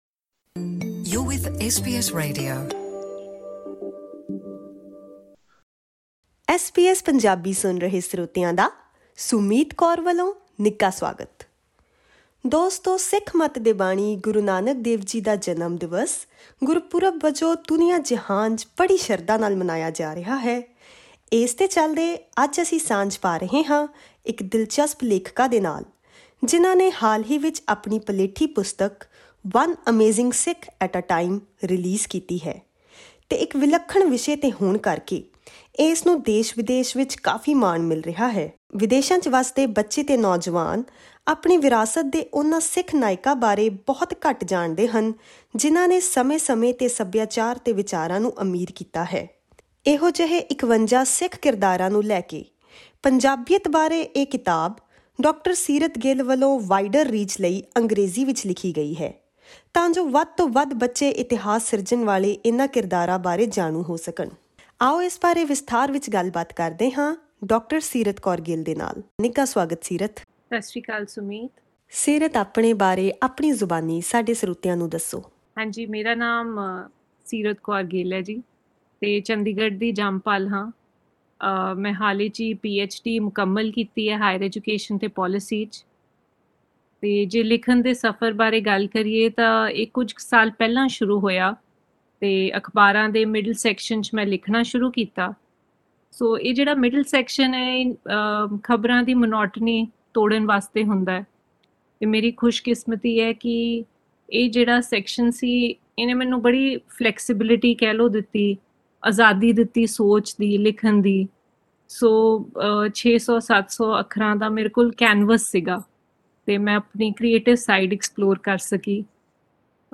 SBS Punjabi View Podcast Series